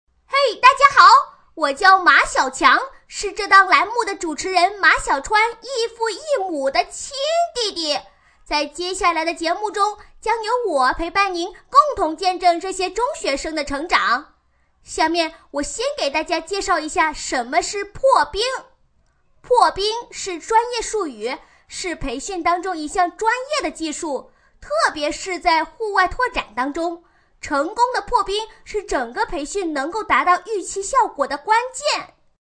【女90号童音】男童2
【女90号童音】男童2.mp3